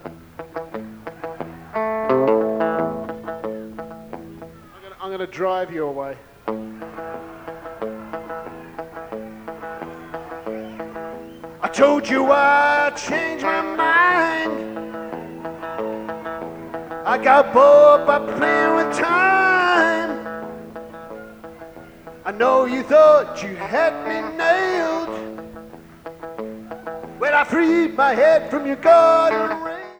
DAT Tape Masters